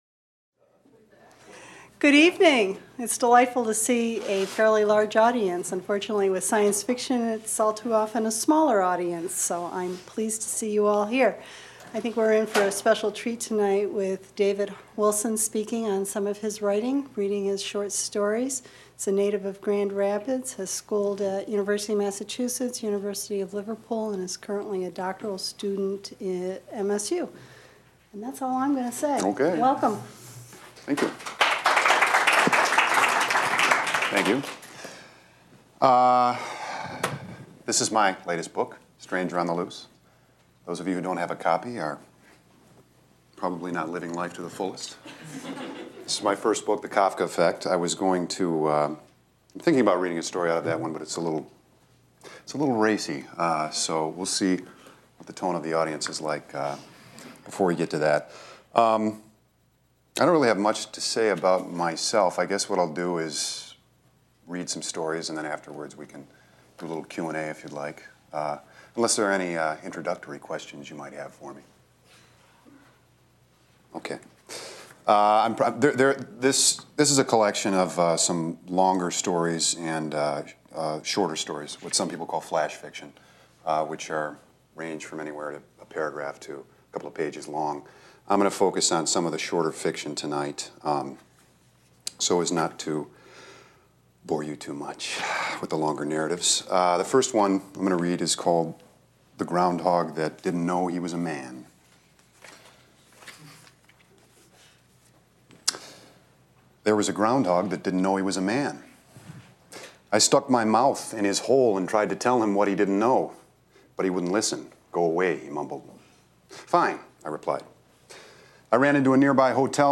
Held in the MSU Main Library.
Recorded at the Michigan State University Libraries by the Vincent Voice Library on Jan. 16, 2004.